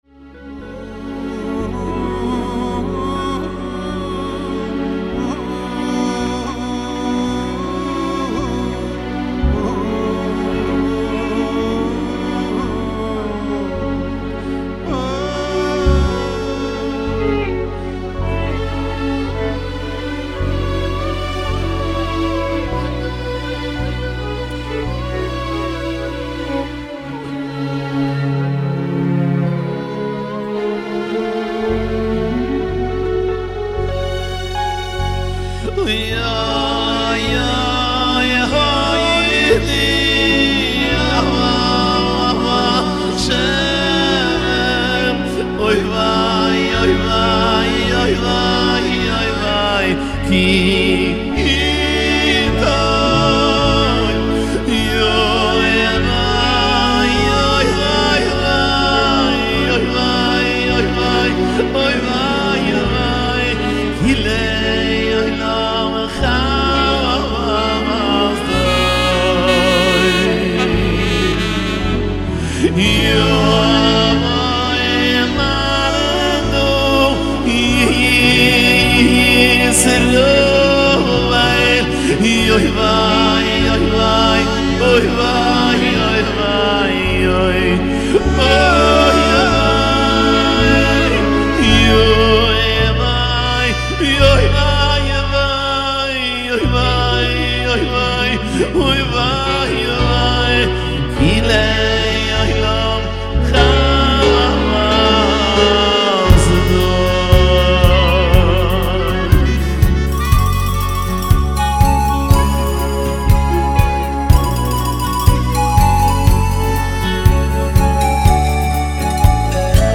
מצו"ב הקלטה של המקצב, מתחיל בדקה 1:46